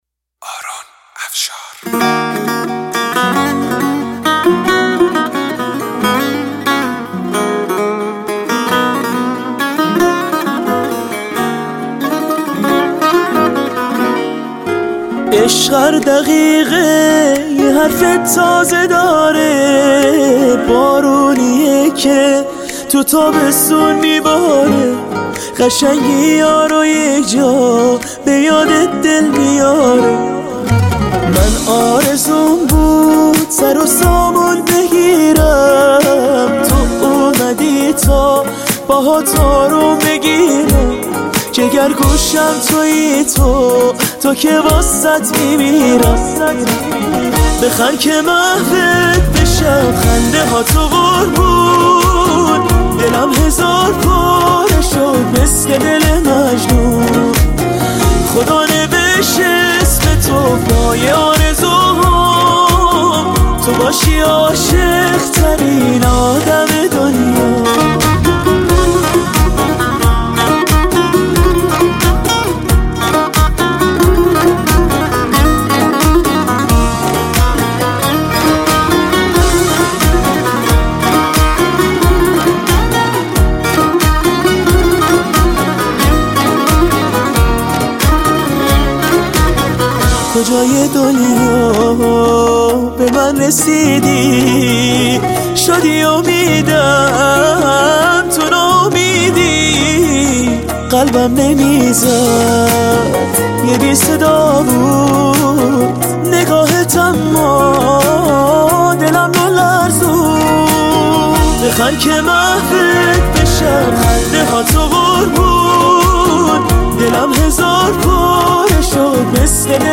پلی بک اورجینال